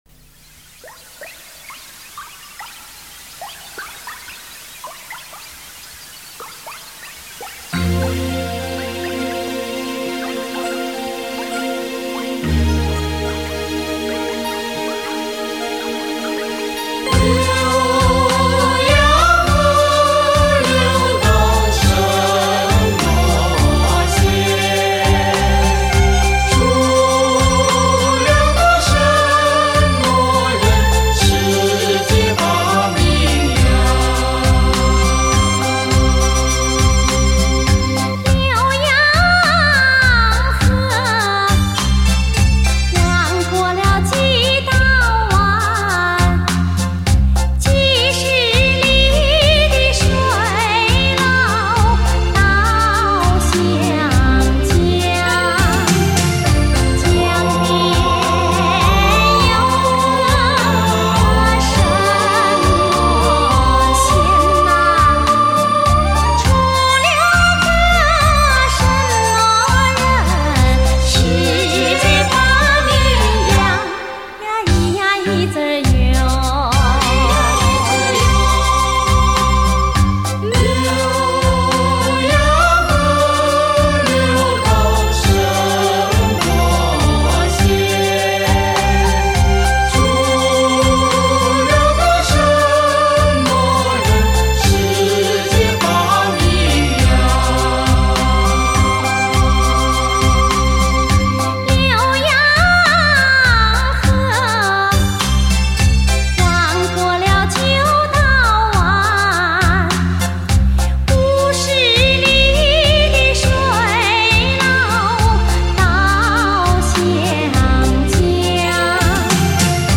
典范发烧人声HIFI经典音乐